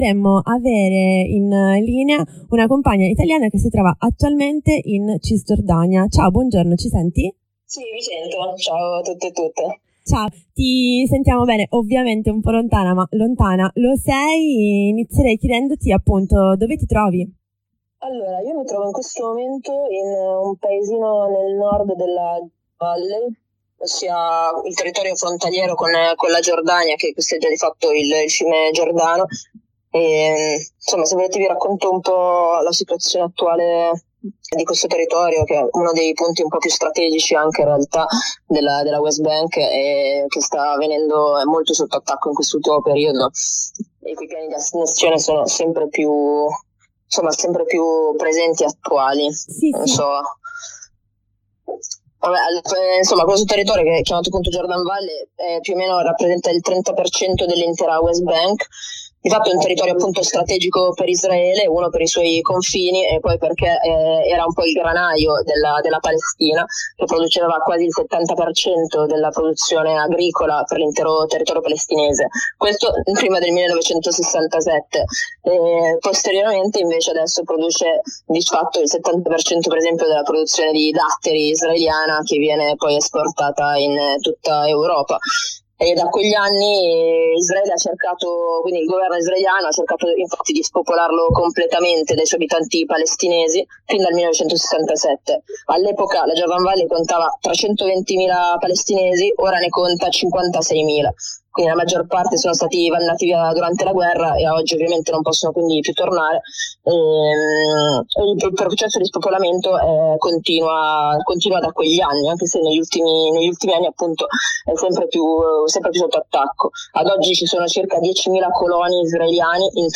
Corrispondenza dalla Jordan Valley
Abbiamo parlato dei piani di annessione della zona C della West Bank da parte di Israele con una compagna che si trova proprio in una zona C nella Valle del Giordano, in Cisgiordania.